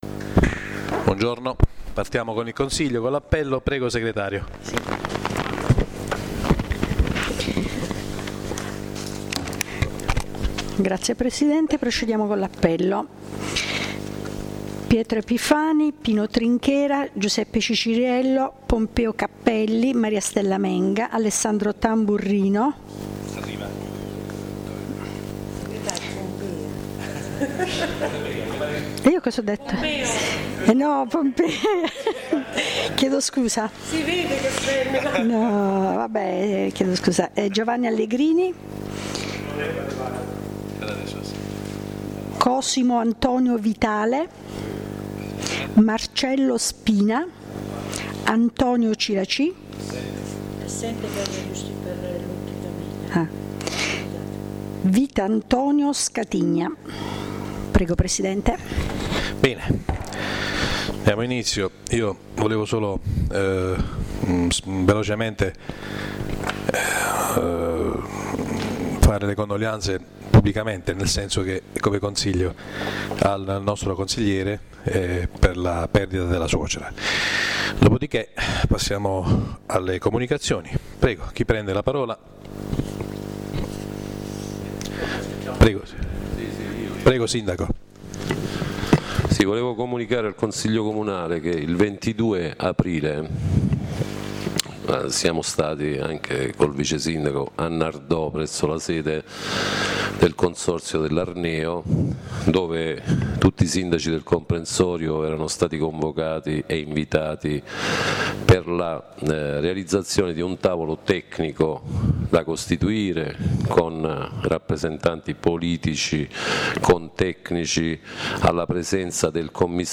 La registrazione audio del Consiglio Comunale di San Michele Salentino del 29/04/2016